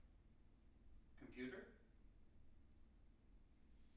wake-word
tng-computer-59.wav